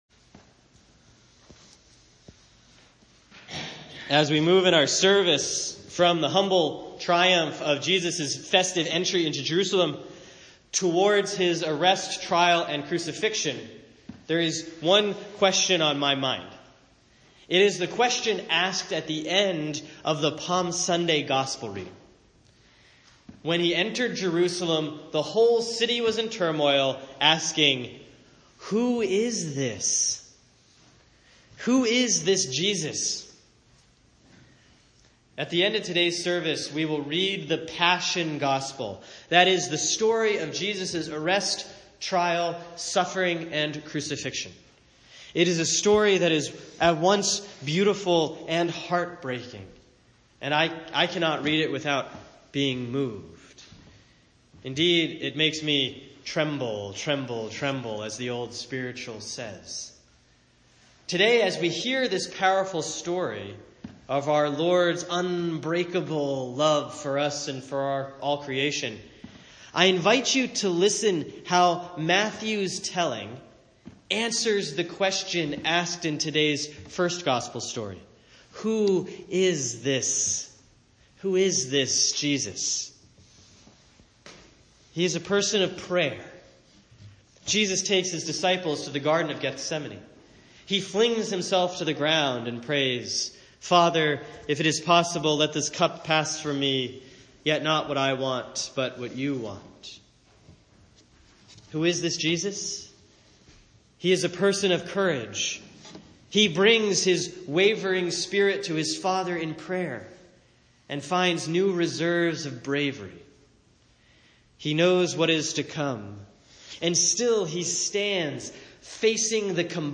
Sermon for Sunday, April 9, 2017 || Palm/Passion Sunday, Year A || Matthew 22:1-11; Matthew 26:36 – 27:56